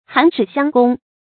函矢相攻 注音： ㄏㄢˊ ㄕㄧˇ ㄒㄧㄤ ㄍㄨㄙ 讀音讀法： 意思解釋： 指自相矛盾 出處典故： 先秦 孟軻《孟子 公孫丑上》：「矢人唯恐不傷人，函人唯恐傷人。」